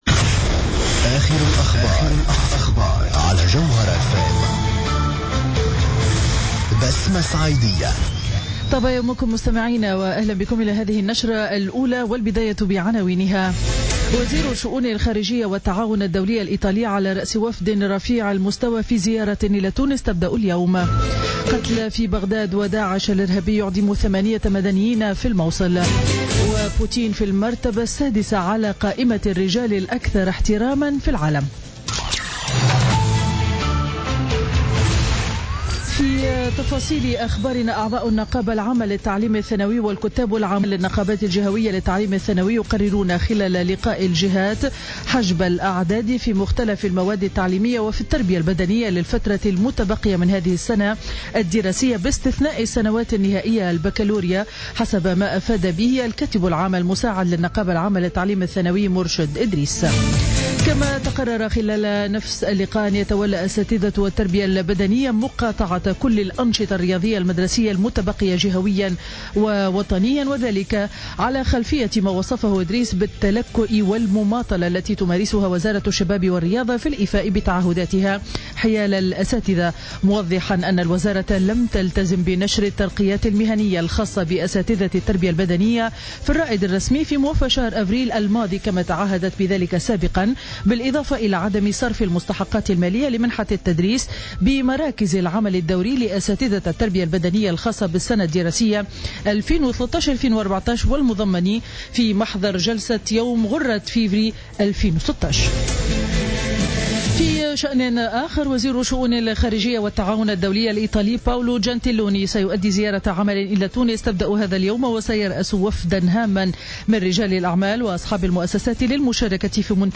نشرة أخبار السابعة صباحا ليوم الإثنين 9 ماي 2016